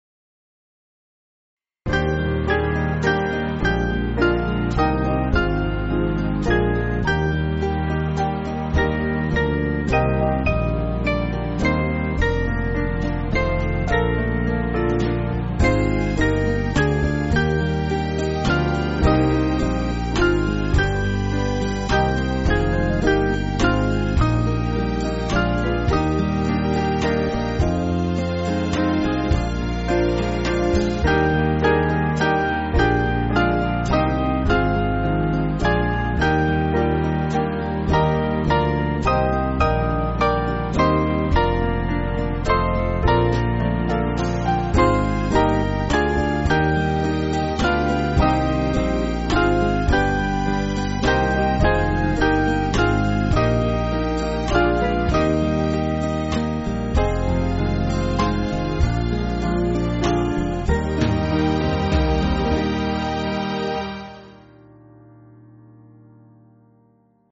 Small Band
(CM)   2/Eb